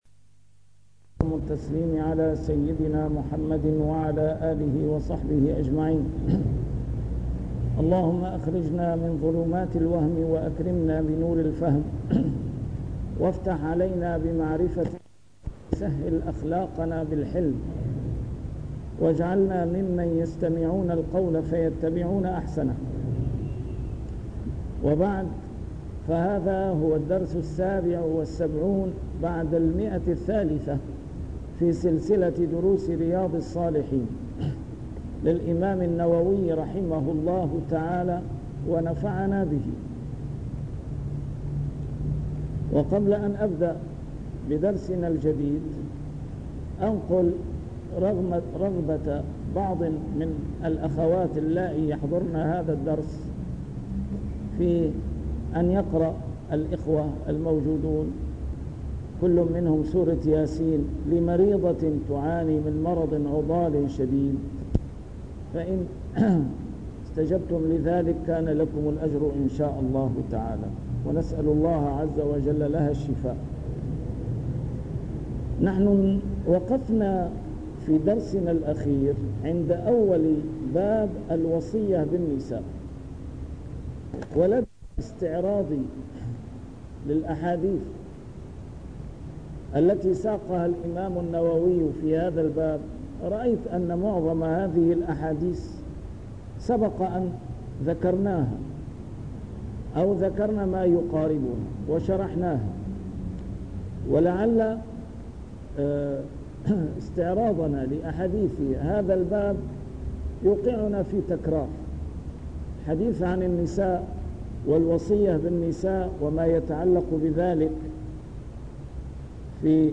A MARTYR SCHOLAR: IMAM MUHAMMAD SAEED RAMADAN AL-BOUTI - الدروس العلمية - شرح كتاب رياض الصالحين - 377- شرح رياض الصالحين: حق الزوج على المرأة